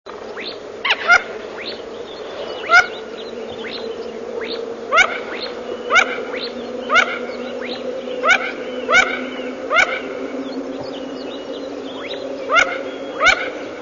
Mandarynka - Aix Galericulata
głosy